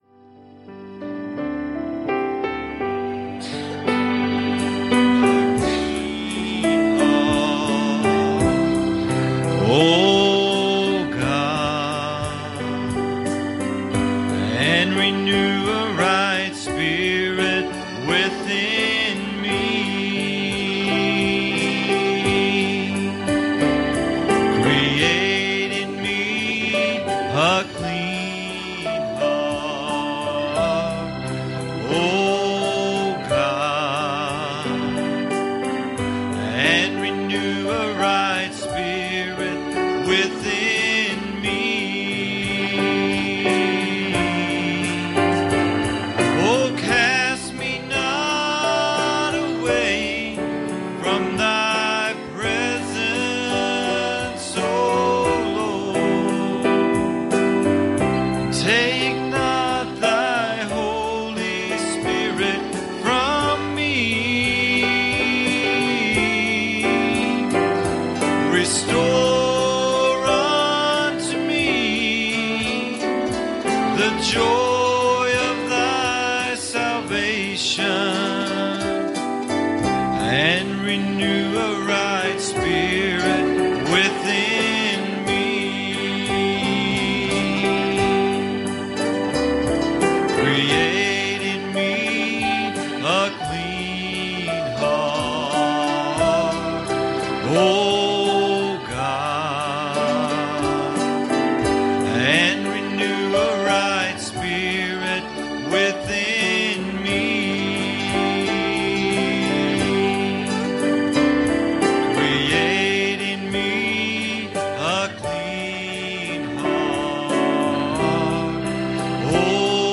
Passage: Exodus 14:15 Service Type: Wednesday Evening